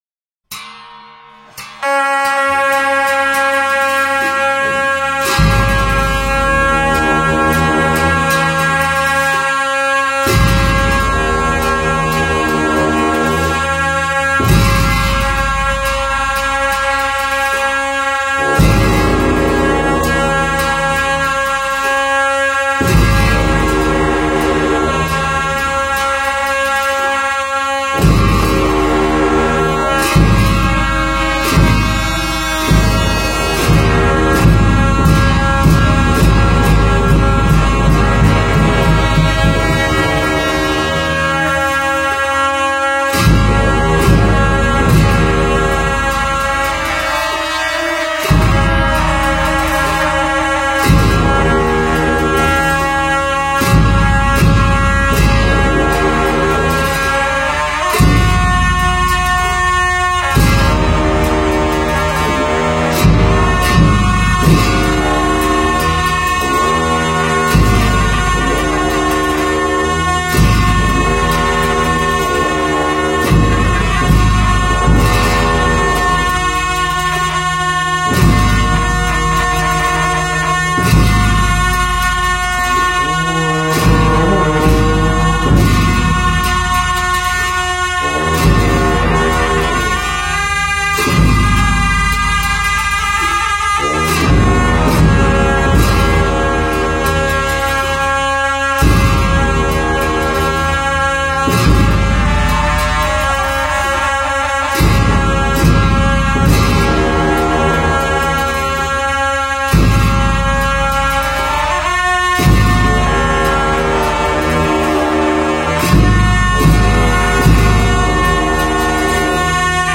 佛音 诵经 佛教音乐 返回列表 上一篇： 峨嵋佛光 下一篇： 忿怒莲师冈梭(二